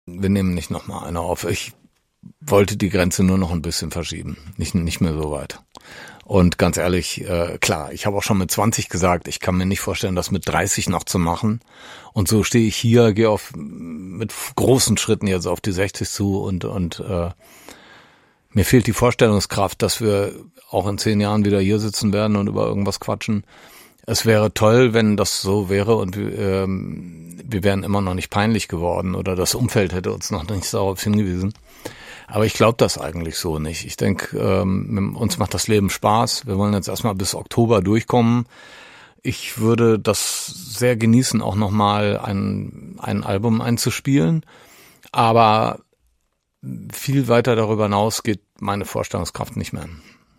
SWR3 Interview Campino über das Ende der Toten Hosen